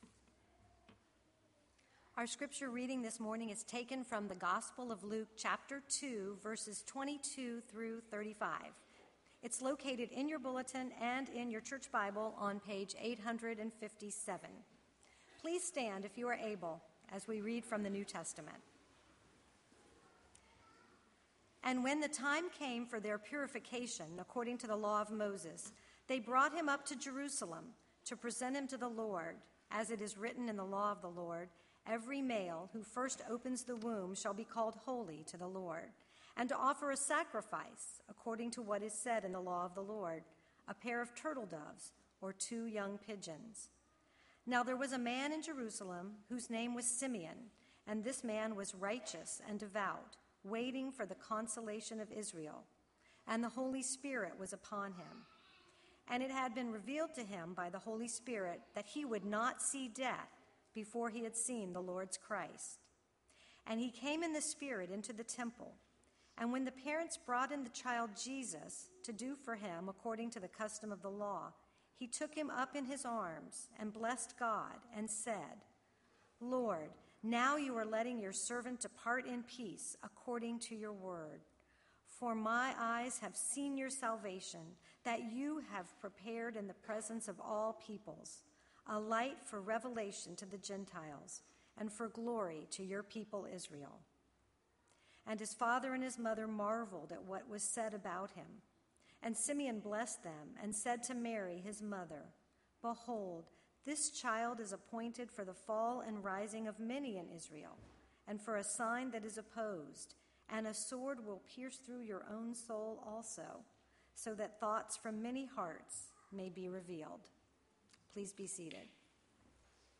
Sermon Luke 2:22-35 (ESV)